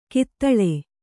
♪ kittaḷe